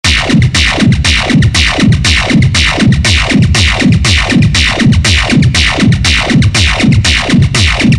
描述：为博丁合成器和古典钢琴+吉他制作的恍惚低音
Tag: 120 bpm Trance Loops Bass Synth Loops 1.35 MB wav Key : Unknown